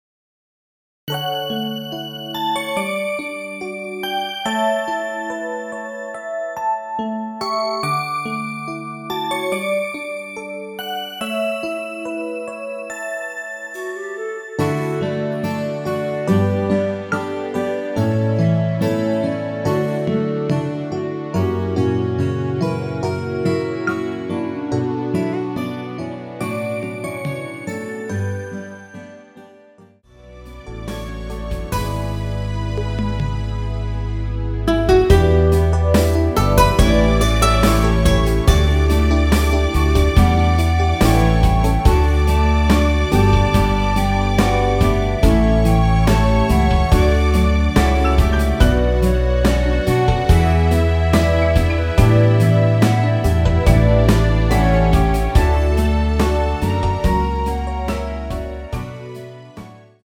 원키에서(+2)올린 멜로디 포함된 MR 입니다.(미리듣기 확인)
앞부분30초, 뒷부분30초씩 편집해서 올려 드리고 있습니다.
중간에 음이 끈어지고 다시 나오는 이유는
축가 MR